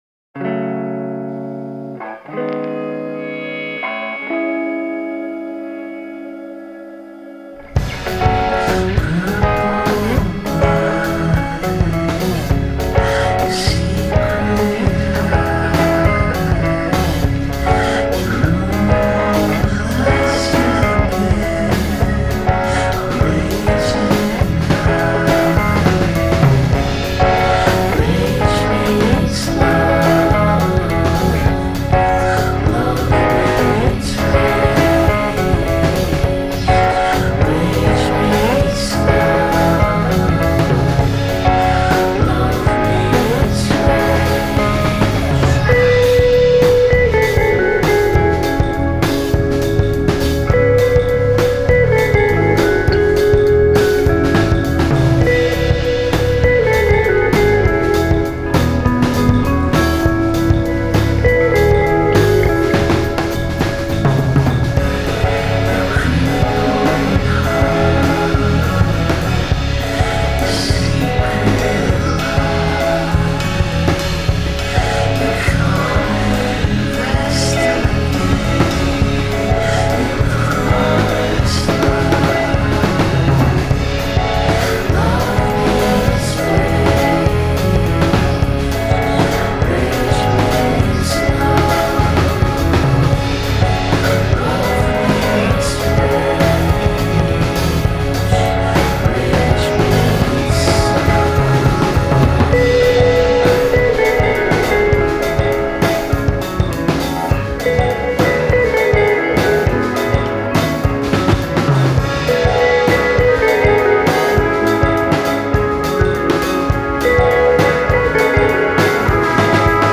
Canadian love children with layered sounds.